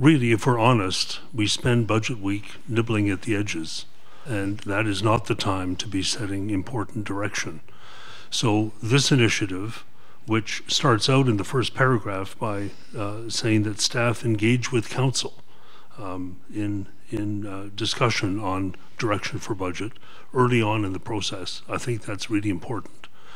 Prince Edward County Council meeting as Committee of the Whole, March 30, 2023.
Councillor John Hirsch fully supported the motion calling it a laudable goal to find new ways to potentially reduce expenditures.